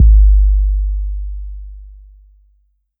808 (Coldest Winter).wav